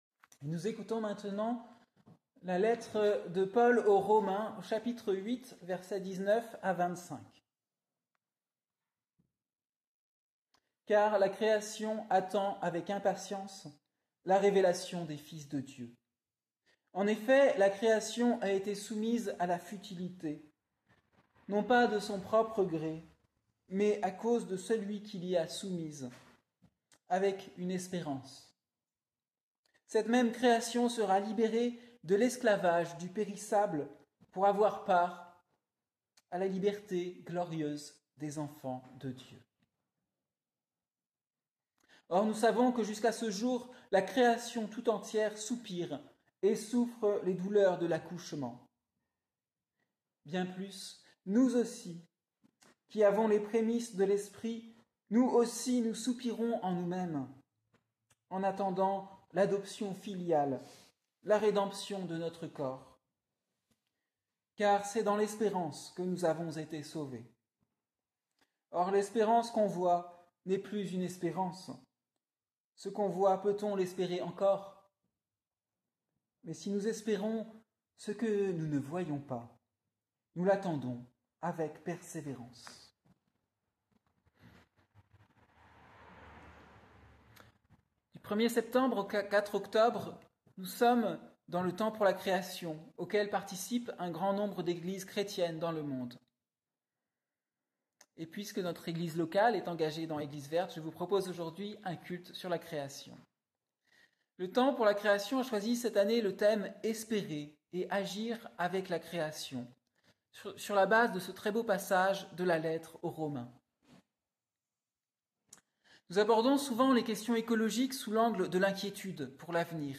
Prédication du dimanche 29 septembre 2024 : temps pour la création